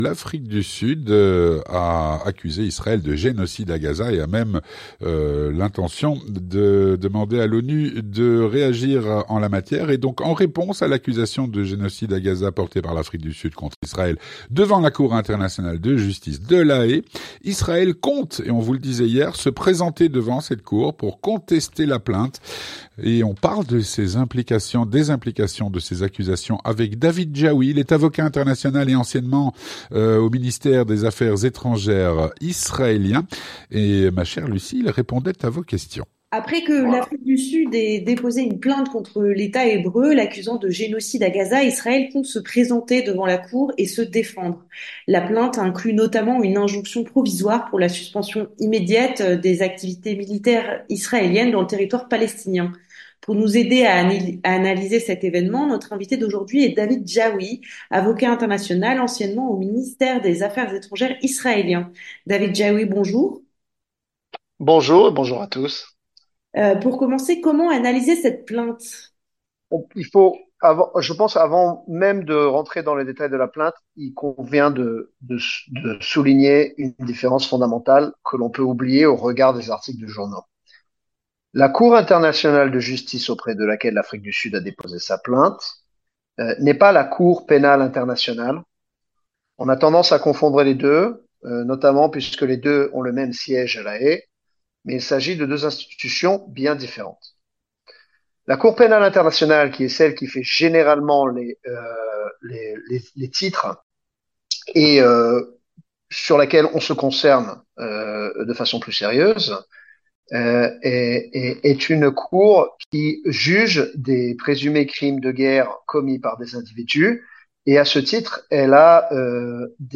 L'entretien du 18H - Israël compte se présenter devant la Cour pour contester la plainte pour "Génocide" à Gaza.